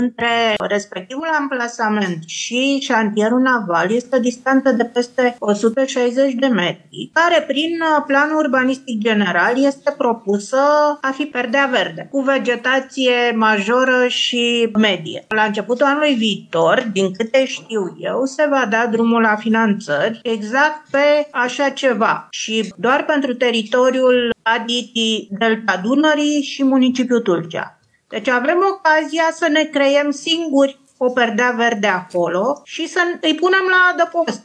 De asemenea, consiliera PSD Anca Suhov a precizat că între teren și Șantierul Naval există o distanță de peste 160 de metri, unde este planificată amenajarea unei perdele verzi: